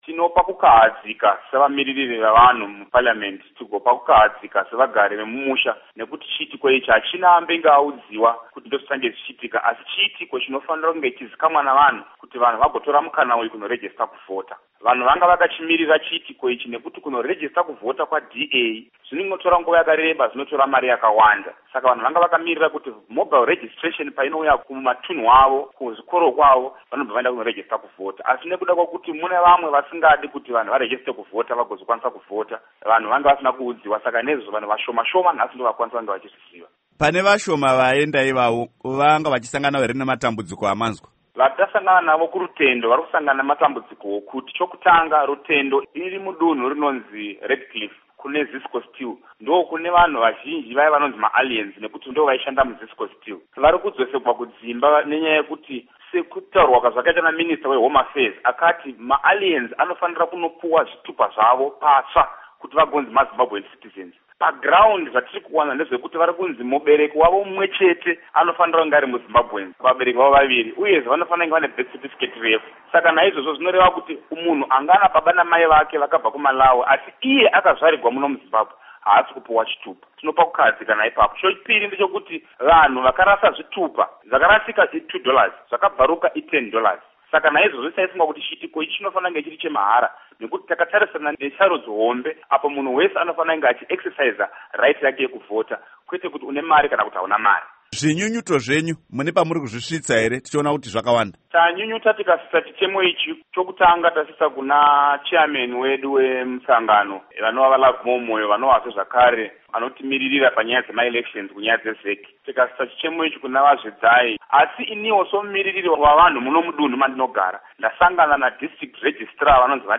Hurukuro naVaSettlement Chikwinya